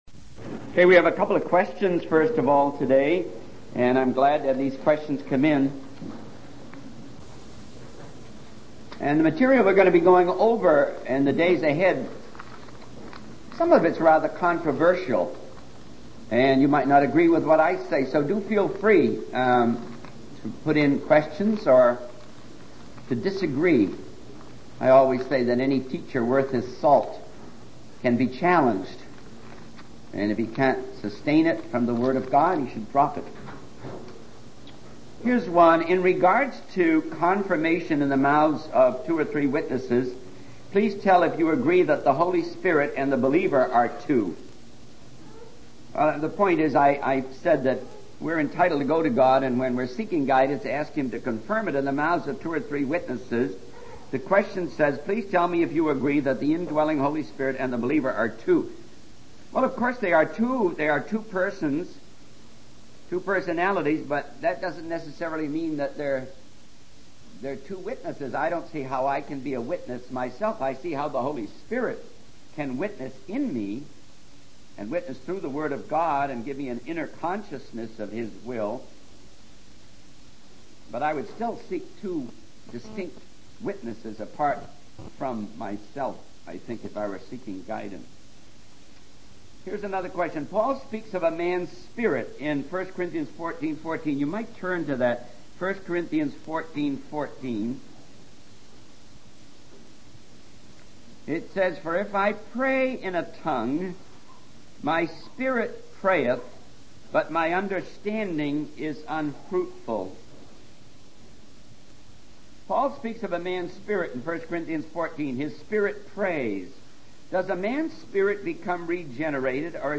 In this sermon, the speaker discusses the concept of spiritual gifts and their importance in Christian life and service. He emphasizes the need to use these gifts in love and humility, rather than becoming proud or seeking personal gain.